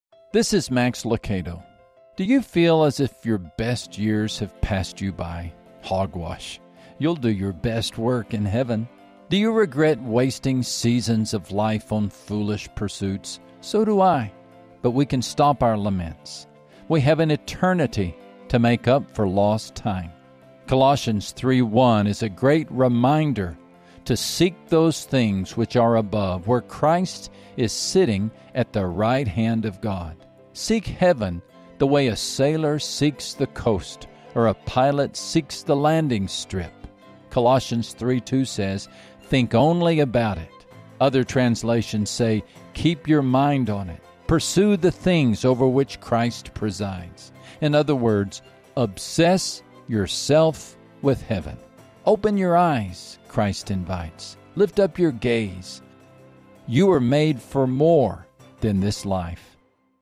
Max shares words of hope and help through simple, one-minute daily devotionals to encourage you to take one step closer to Jesus.